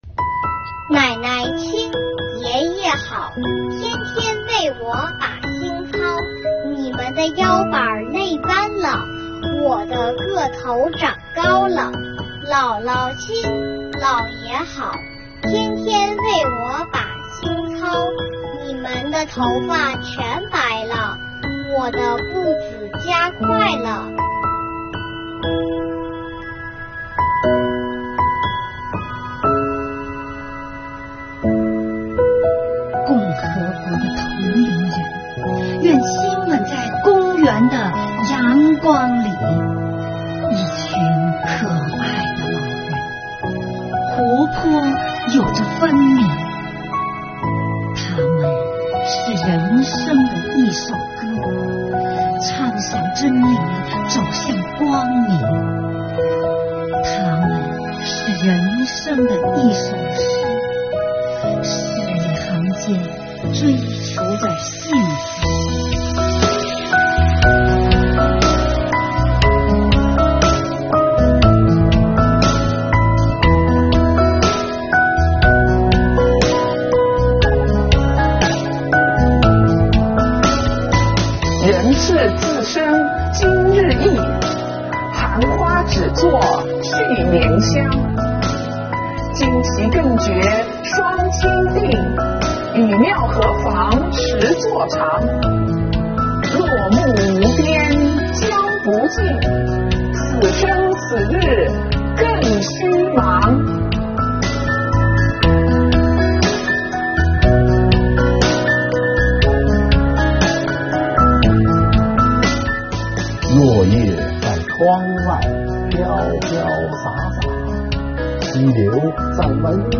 我们邀请几位退休税务干部，共同完成了《革命者“咏”远是年轻》这一特别策划，把祝福送给可敬可爱的老人们。